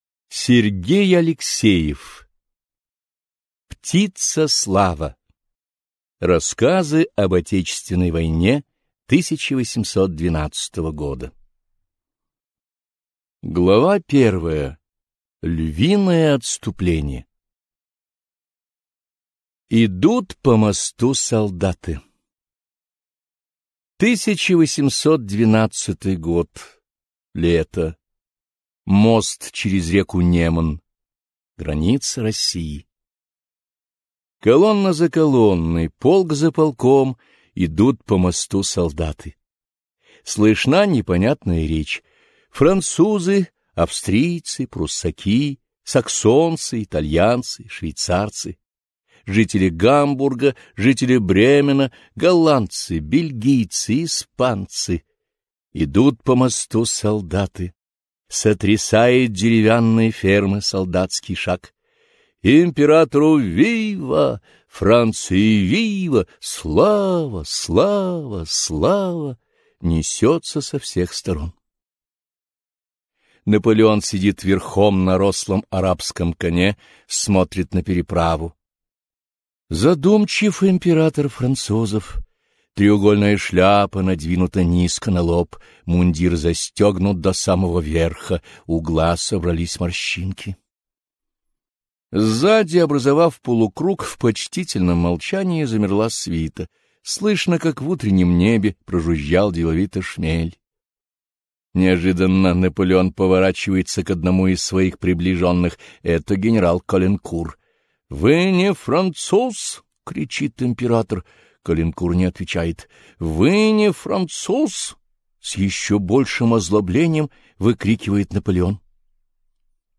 Аудиокнига Рассказы об отечественной войне 1812 года | Библиотека аудиокниг